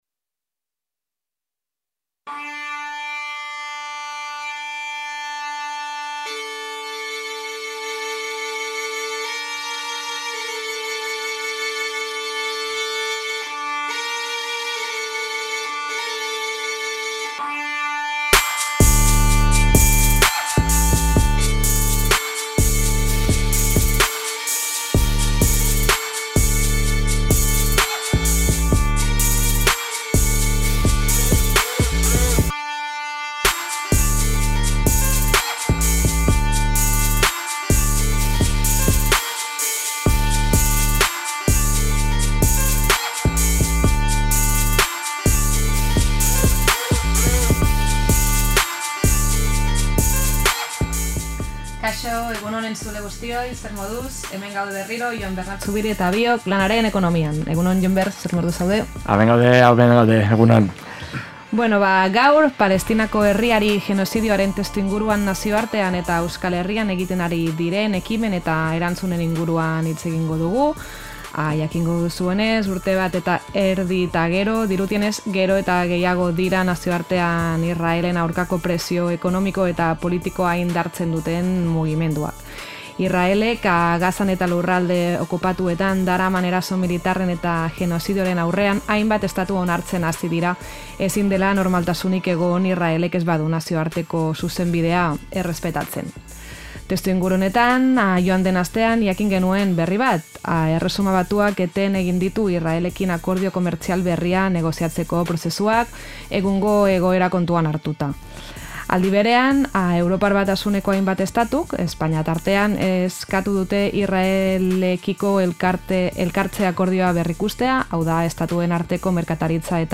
Gaurko Lanaren Ekonomia saioan testuingurua hobeto ulertzeko, hiru elkarrizketa ekarri dizkizuegu: Lehenik